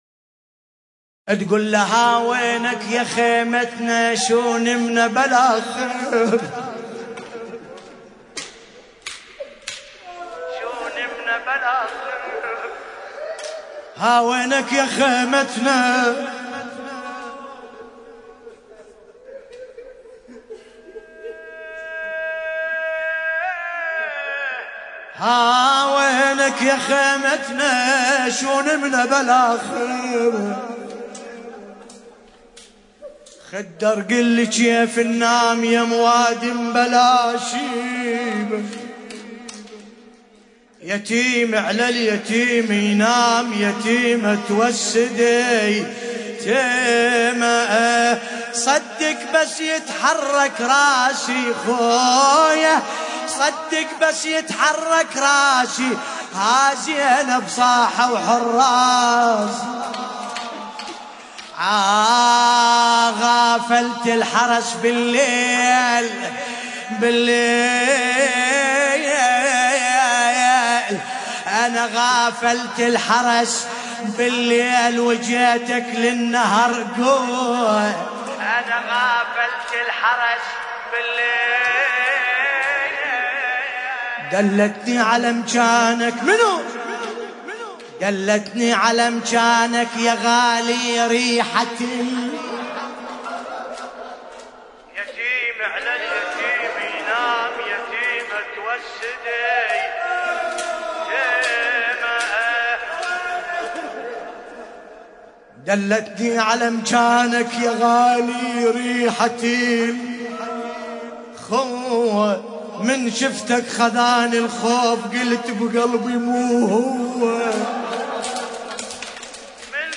المناسبة : اسشتهاد ابا الفضل العباس (ع)
المكان : هيئة شباب علي الأكبر - لندن